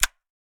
Bail Open.ogg